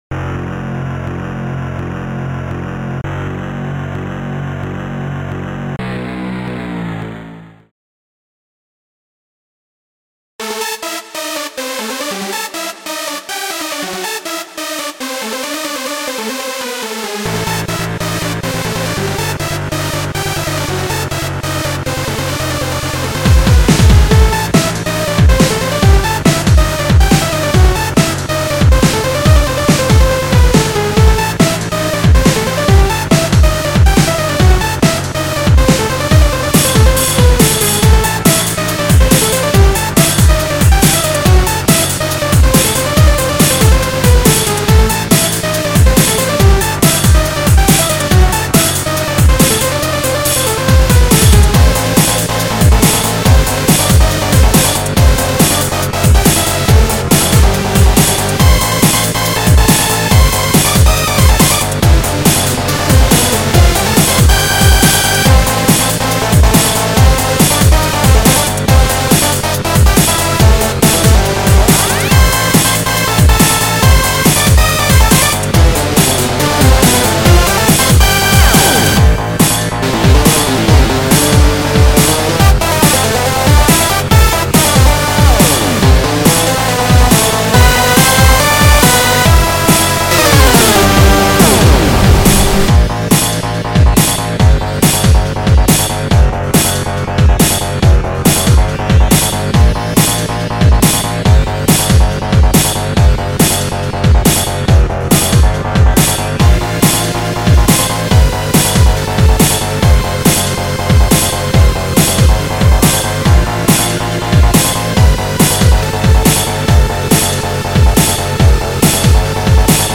the ride and snare overlapping does annoy me a little bit
dnb/big beat song 150 bpm ? made with fl
rave dnb drumnbass breakbeat